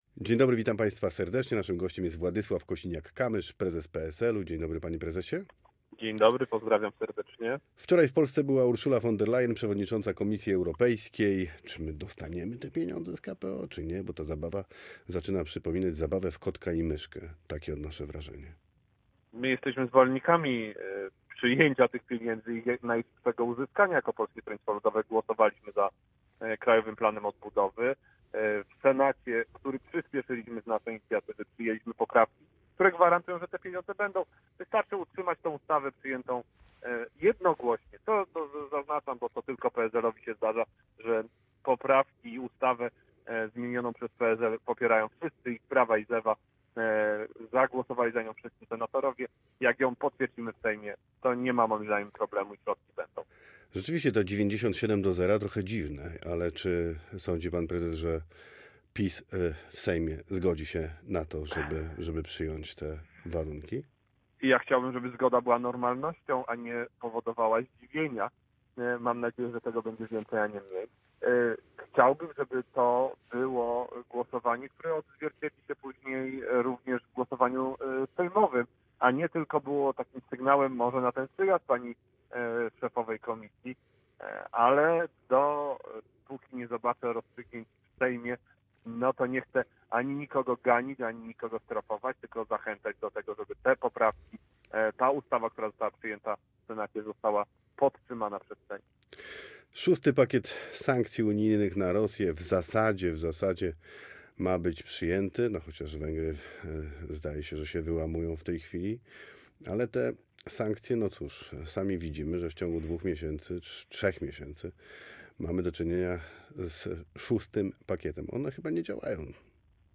Posłuchaj całej rozmowy: Nazwa Plik Autor Władysław Kosiniak-Kamysz | Gość po 8 audio (m4a) audio (oga) WCZEŚNIEJSZE ROZMOWY DNIA ZNAJDZIESZW ARCHIWUM Warto przeczytać Kolejny transfer Widzewa!